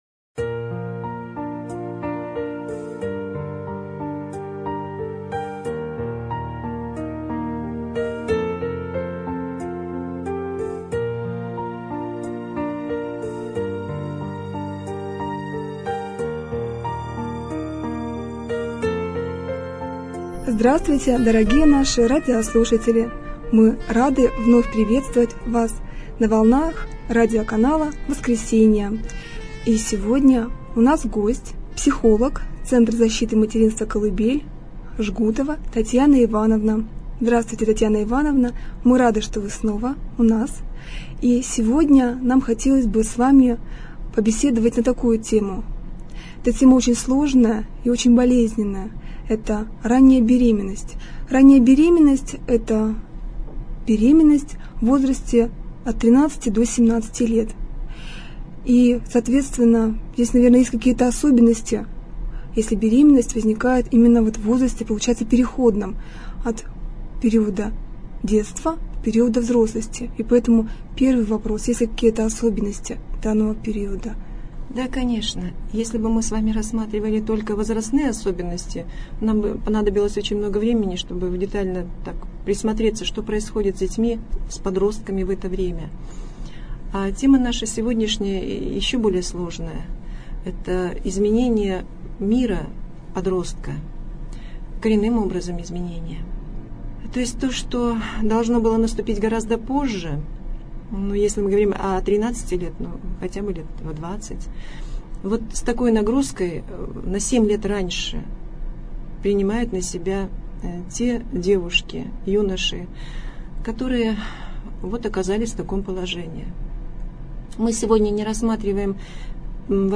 Беседа про раннюю беременность